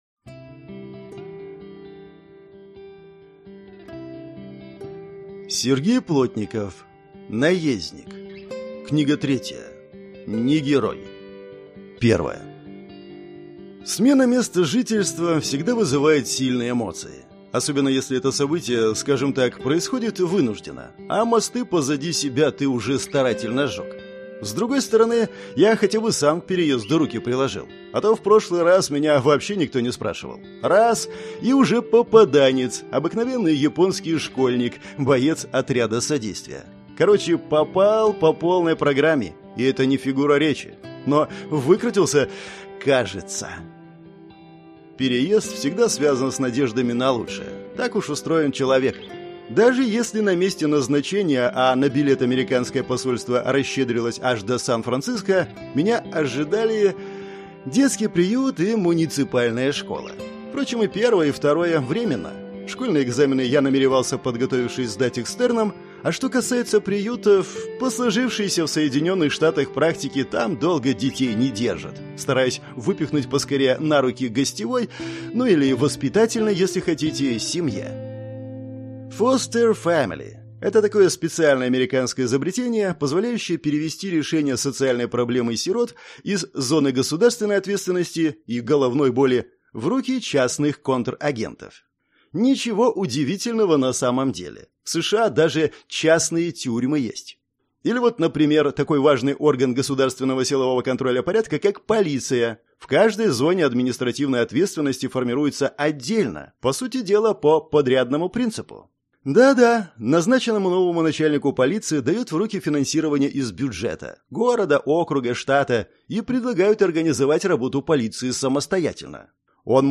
Аудиокнига Не герой - купить, скачать и слушать онлайн | КнигоПоиск